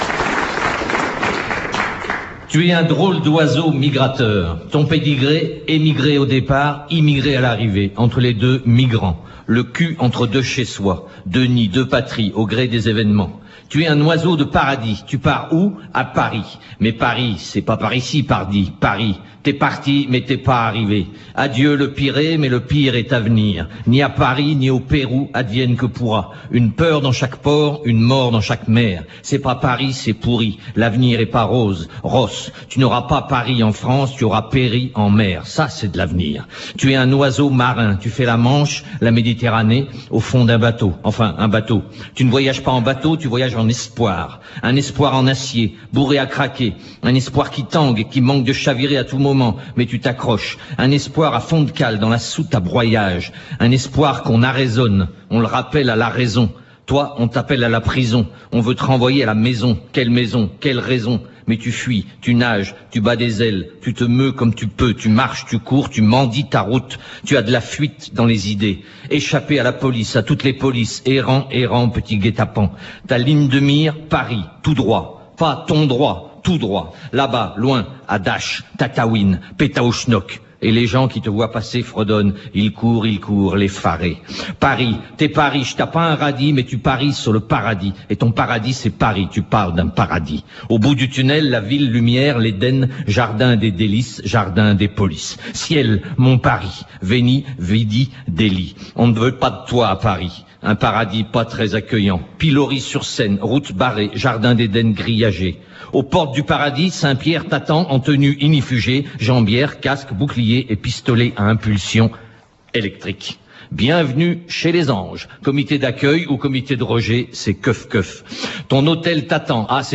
A l’heure où Eric Besson reprend le flambeau de Brice Hortefeux, tentant de marquer sa différence, tout en ne remettant nullement en cause les quotas et autres objectifs chiffrés en termes d’expulsions, Vincent Roca se livre à un petit numéro poético-humoristique…